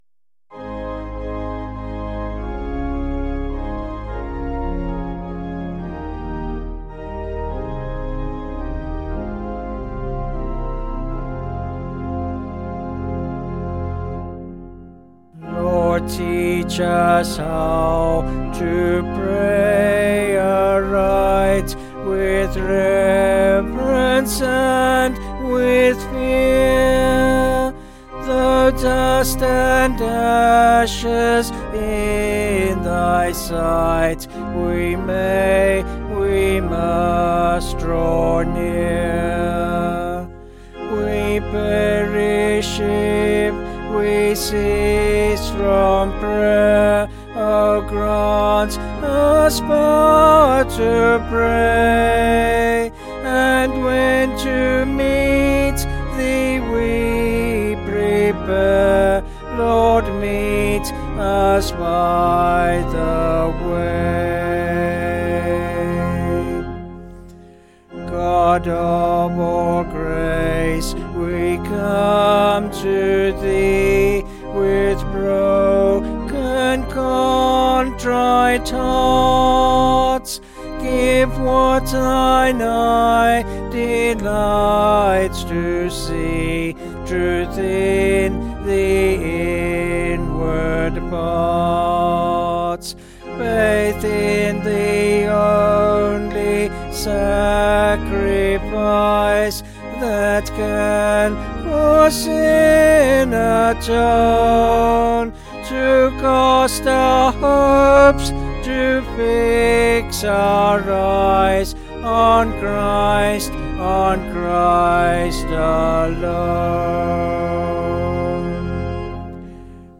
Vocals and Organ   705.2kb Sung Lyrics